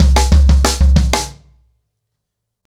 Wireless-90BPM.25.wav